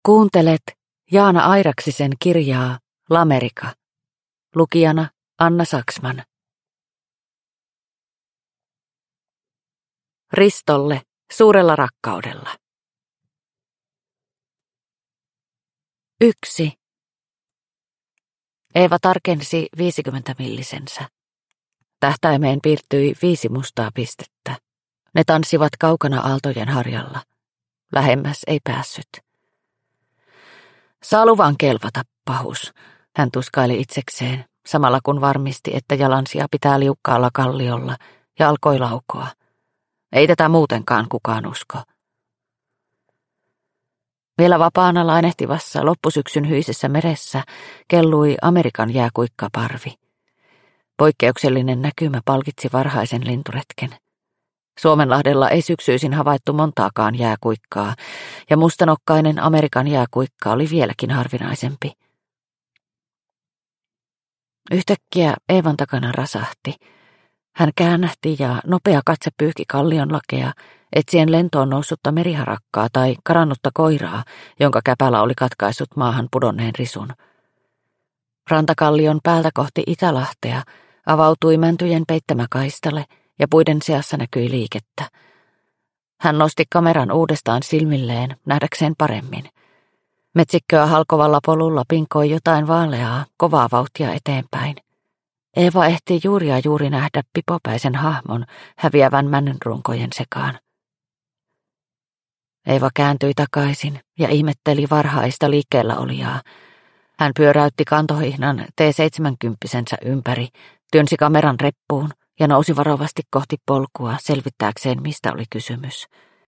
Lamerica – Ljudbok – Laddas ner